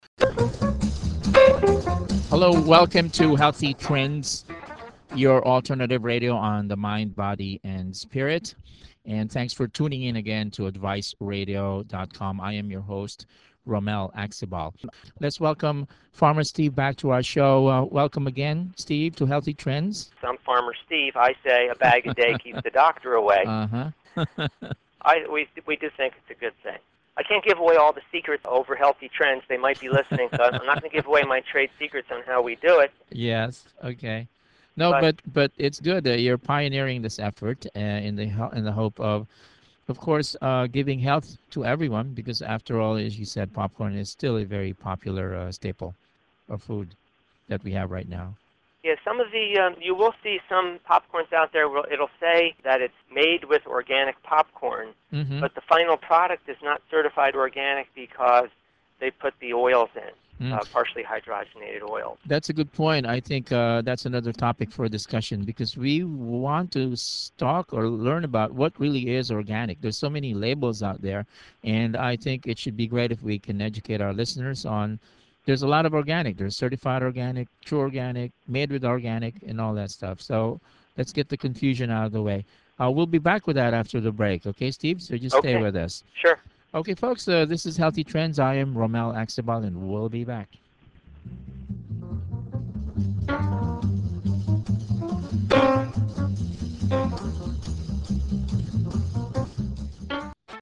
Hear excerpts of the show by clicking play on the below sound clips: